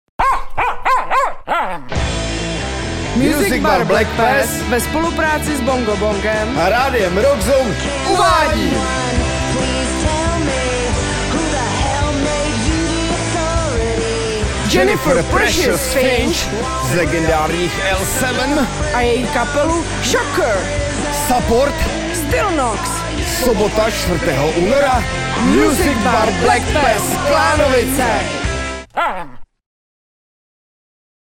radiospot .mp3